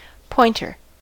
pointer: Wikimedia Commons US English Pronunciations
En-us-pointer.WAV